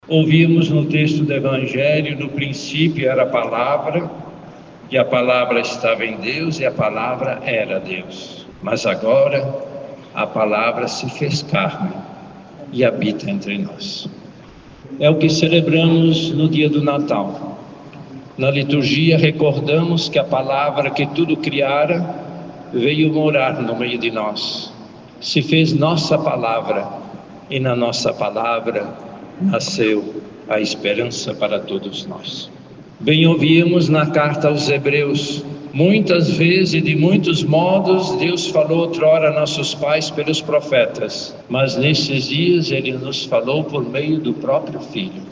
Missa de Natal é realizada na Catedral Nossa Senhora da Conceição
Durante a celebração, o Cardeal da Amazônia desejou um abençoado Natal para todos, salientando que nascemos para a fé, para o amor e a fraternidade, sobretudo na época do Natal, quando celebramos o nascimento daquele que veio para nos perdoar dos pecados.